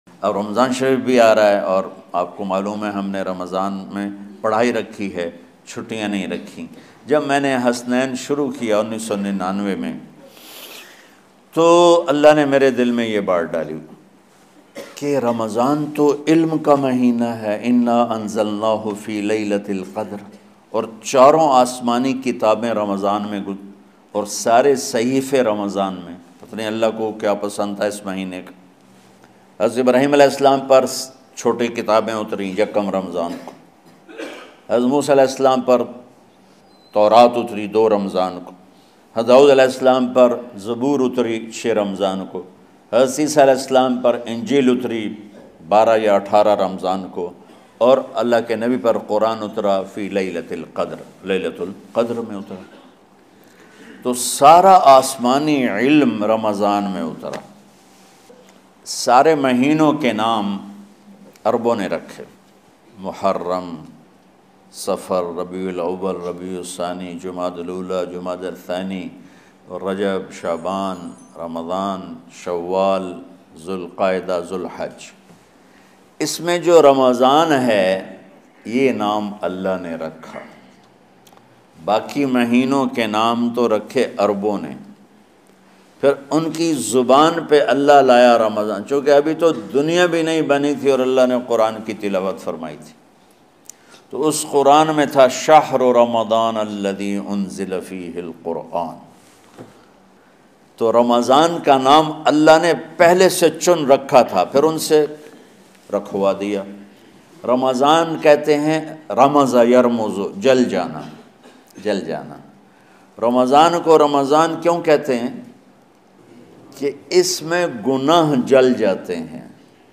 Ramadan Main Paish Aane Wale Waqiat Molana Tariq Jameel Latest Bayan play online & download.